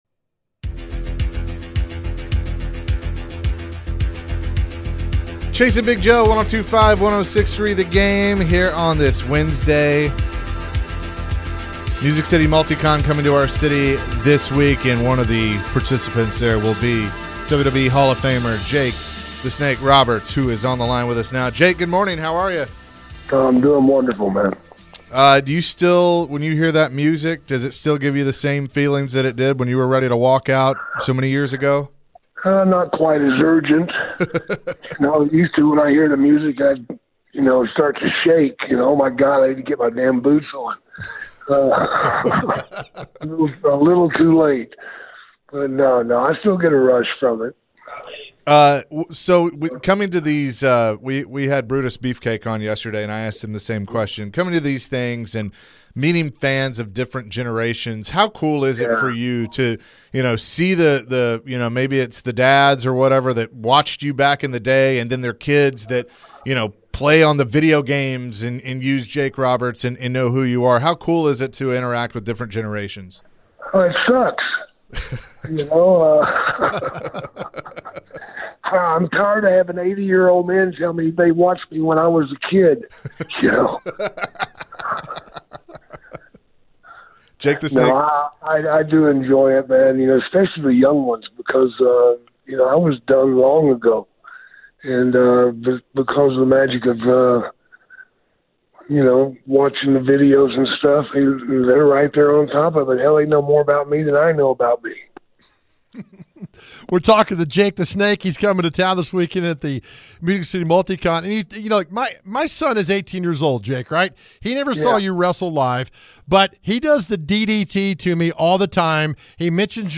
WWE Hall Of Famer Jake The Snake Roberts joined the show and talked about his journey through the WWE. He talked about what he is doing now and what it is like to be known for what he has done being a legend in the ring.